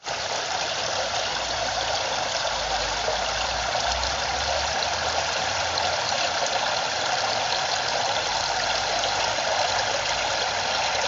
自来水
描述：在池中落水..
Tag: 水花四溅 运行